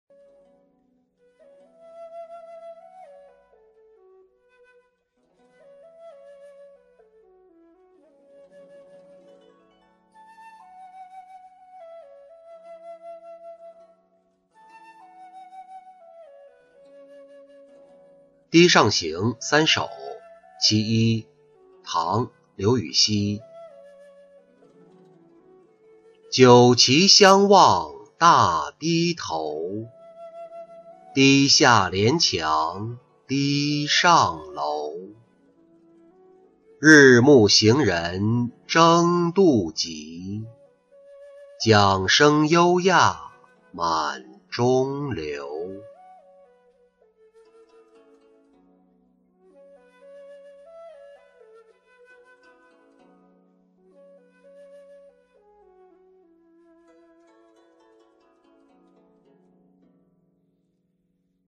堤上行三首.其一-音频朗读